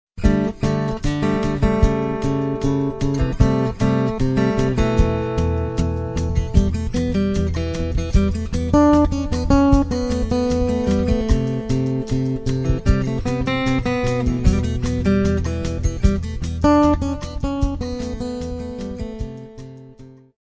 chitarra acustica, percussioni.
basso elettrico, basso fretless.